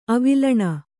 ♪ avilaṇa